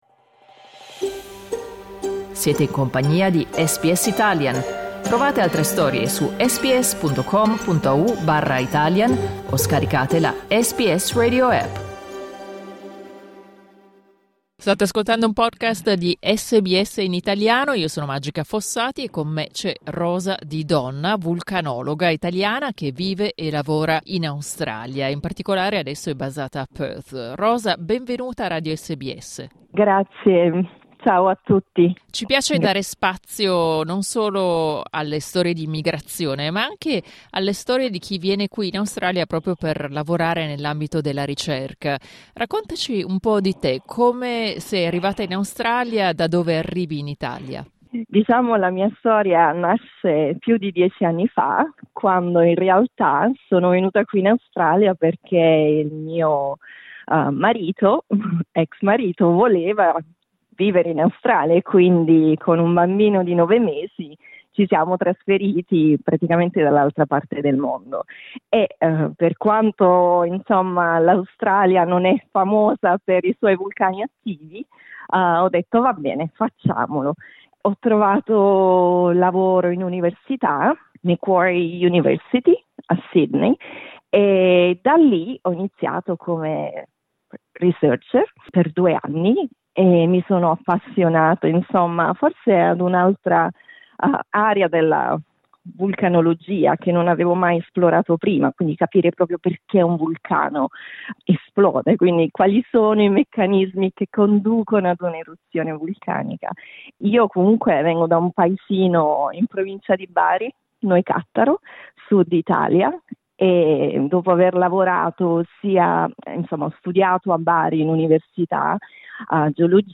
Clicca sul tasto "play" in alto per ascoltare l'intervista integrale